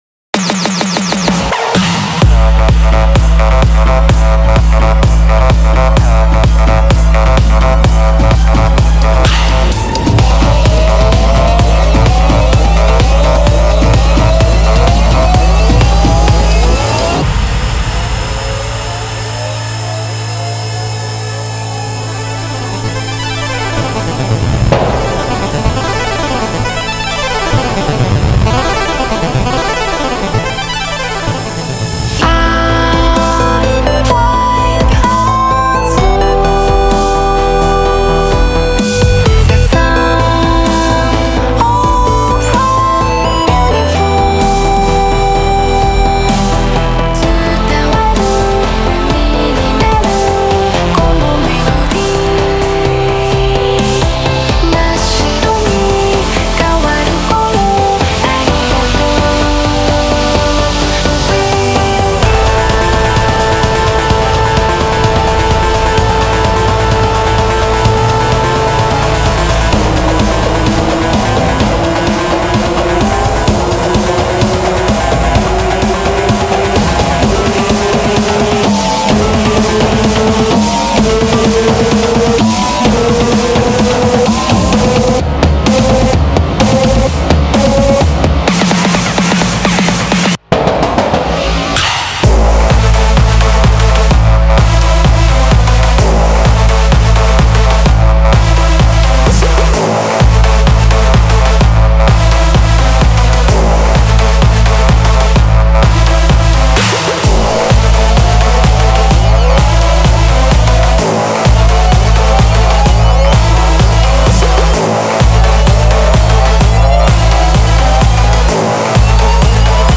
BPM64-128
Audio QualityPerfect (High Quality)
Comments[HARD EDM]